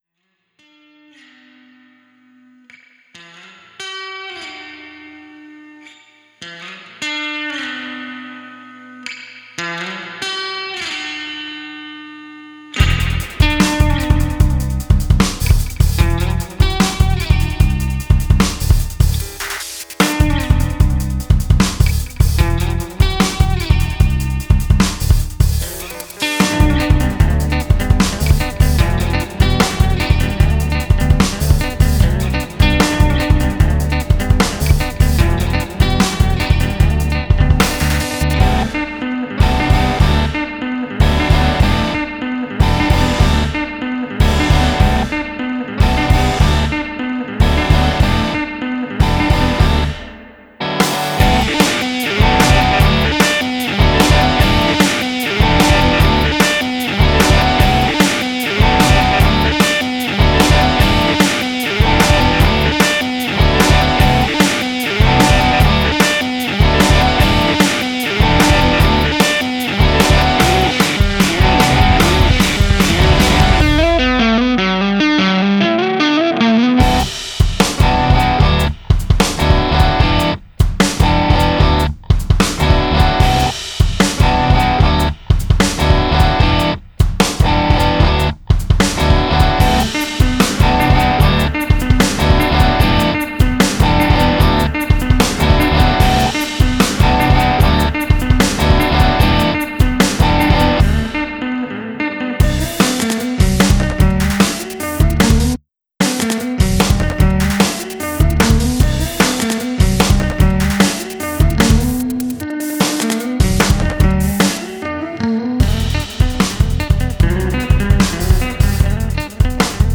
Math Rock genre